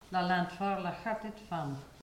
Mots Clé foin, fenaison ; Localisation Perrier (Le) ( Plus d'informations sur Wikipedia ) Vendée
Catégorie Locution